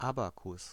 Ääntäminen
Synonyymit Spielbrett Rechenhilfsmittel Säulenelement Ääntäminen : IPA: [ˈa.ba.kʊs] Haettu sana löytyi näillä lähdekielillä: saksa Käännös Substantiivit 1. helmitaulu Artikkeli: der .